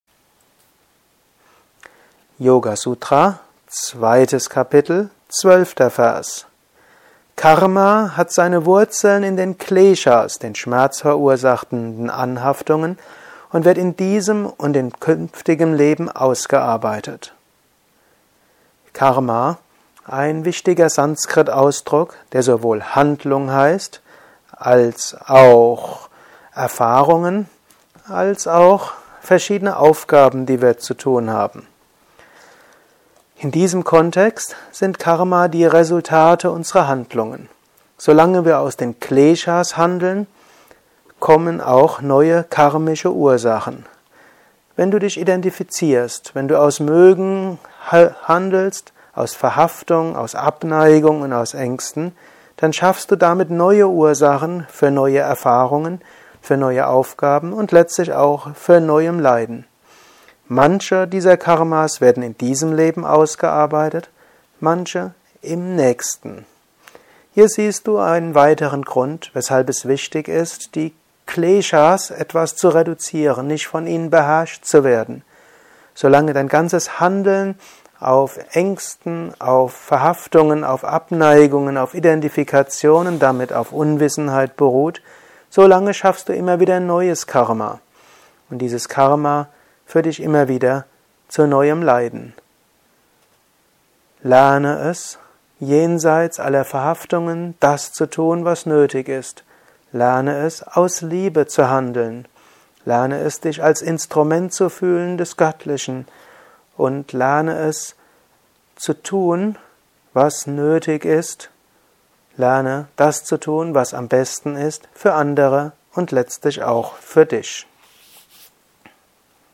gehalten nach einer Meditation im Yoga Vidya Ashram Bad Meinberg.